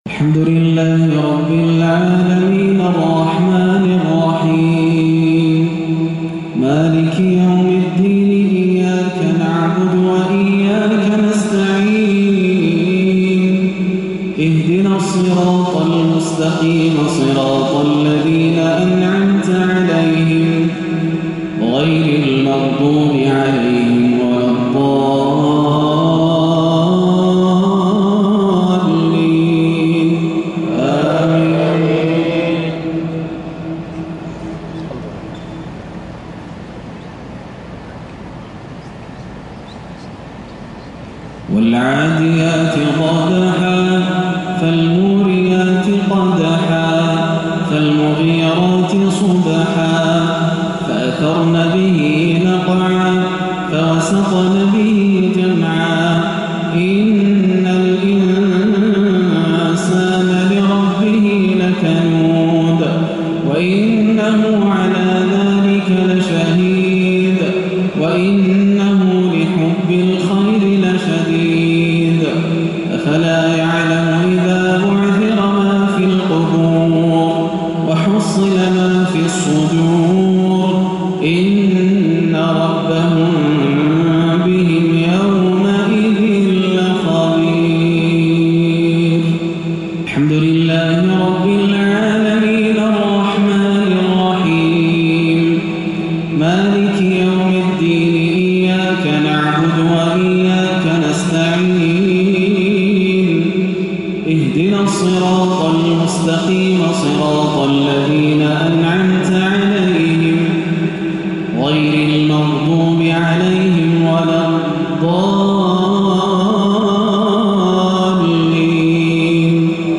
سورتي العاديات والكافرون - مغرب الثلاثاء 3-8-1437هـ > عام 1437 > الفروض - تلاوات ياسر الدوسري